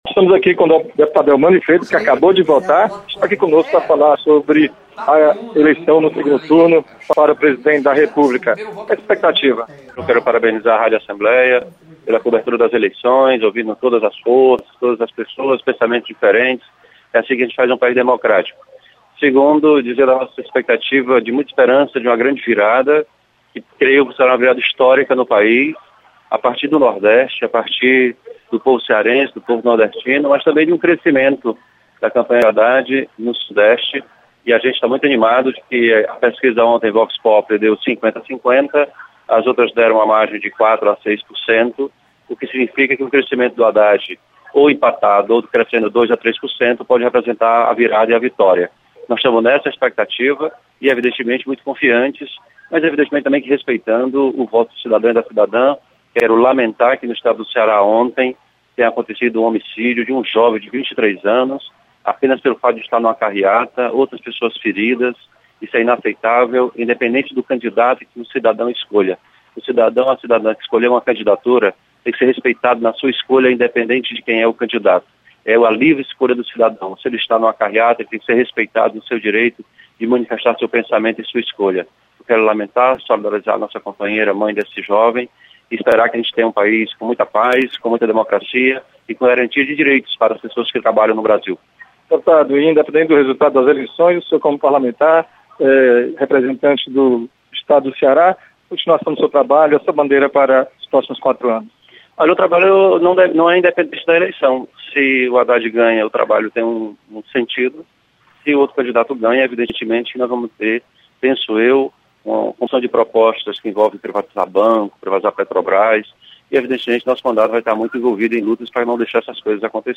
Deputado Elmano de Freitas analisa segundo turnno das eleições 2018.